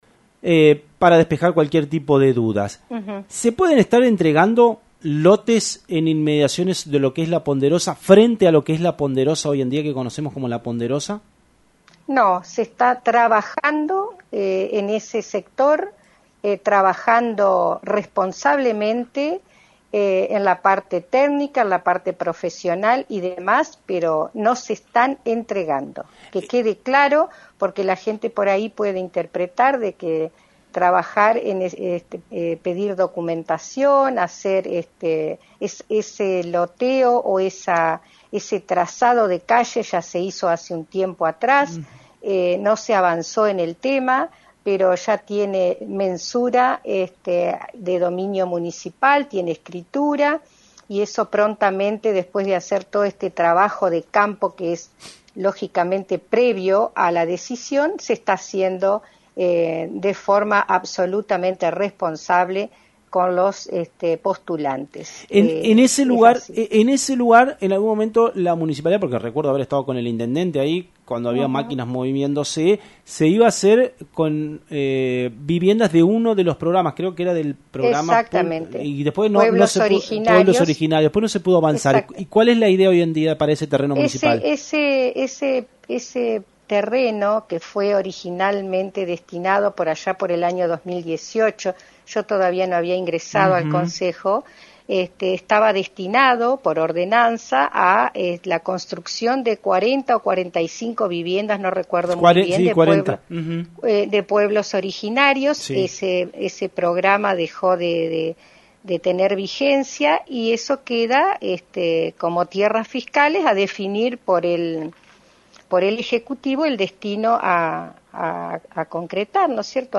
Consultada por FM90.3 la viceintendente Ana Schuth confirmo los trabajos aunque no brindo precisiones si los terrenos se entregaran a días de dejar la gestión. Si confirmo que cuentan en el departamento registros de la municipalidad con 300 legajos de familias carenciadas y adelanto que no se inscribirán mas familias.
Ana Schuth en FM90.3